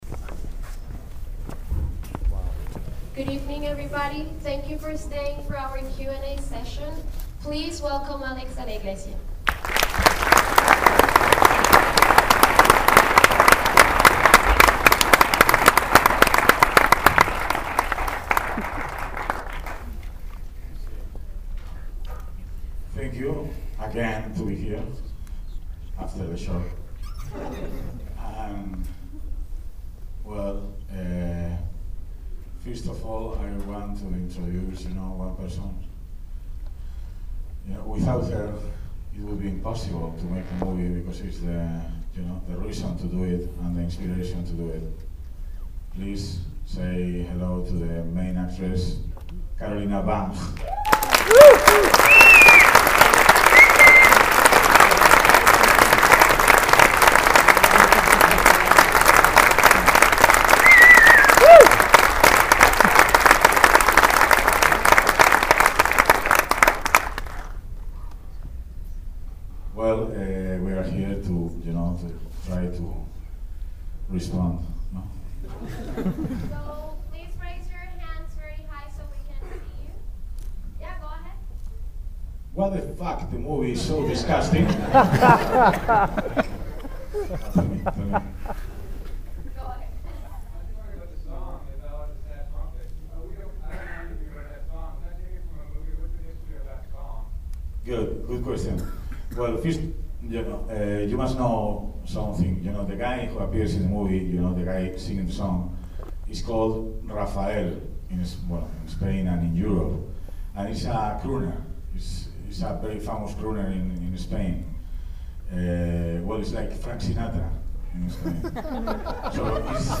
baladatriste_qa.mp3